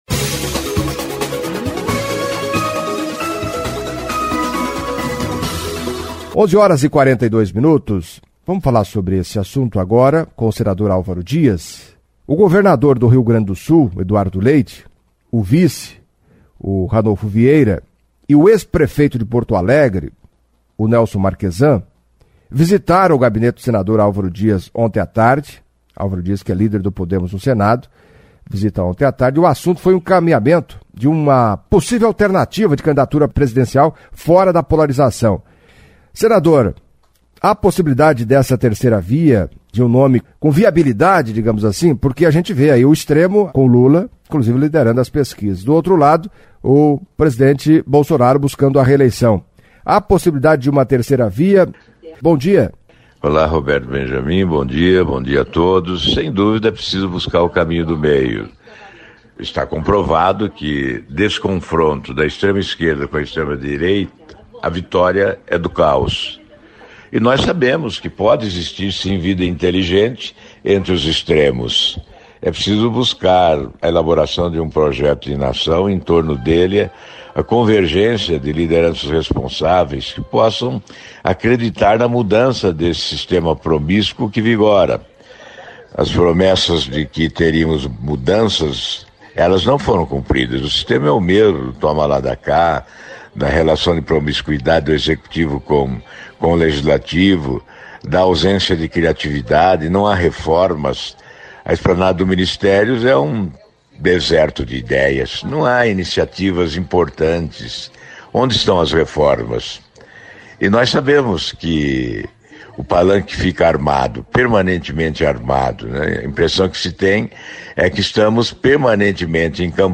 Em entrevista à CBN Cascavel nesta quinta-feira (05) o senador Alvaro Dias, líder do Podemos no Senado, falou da visita que recebeu ontem à tarde do governador do Rio Grande do Sul, Eduardo Leite do PSDB, e da necessidade de uma terceira via na corrida presidencial de 2022.